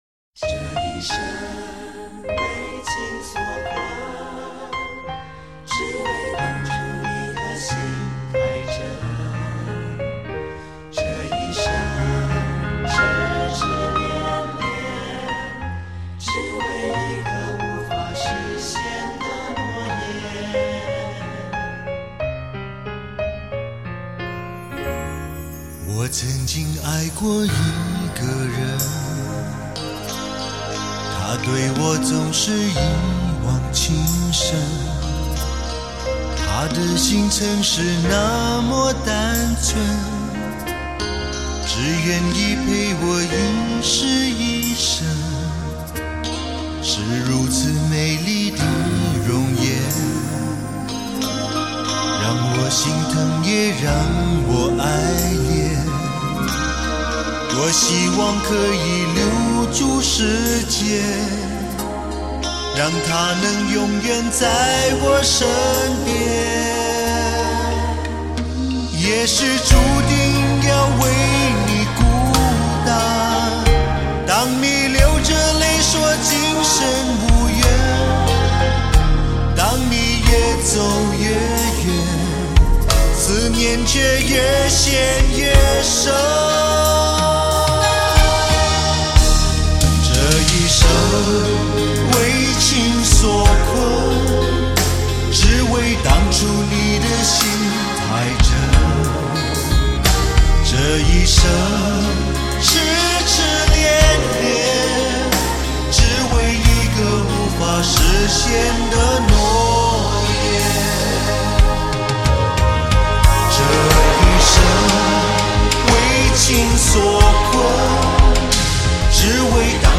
触动心弦纵情感怀的绝色男声，忠实还原慰以情伤的主流热门旋律，